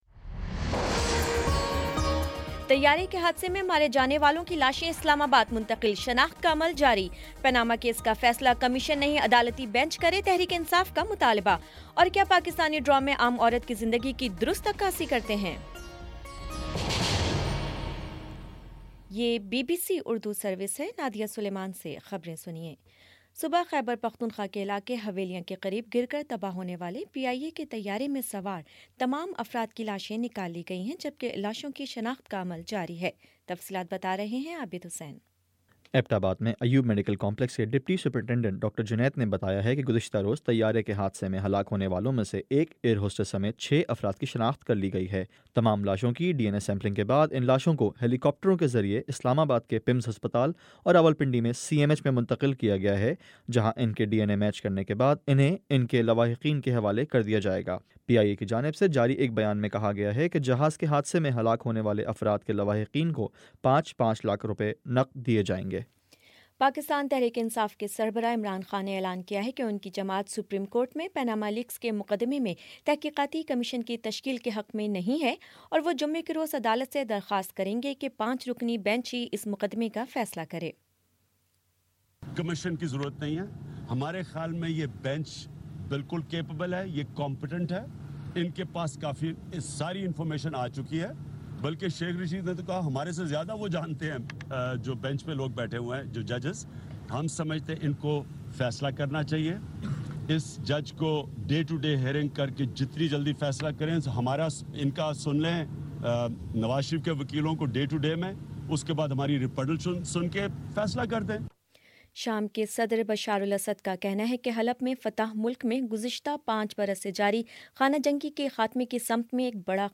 دسمبر 08 : شام چھ بجے کا نیوز بُلیٹن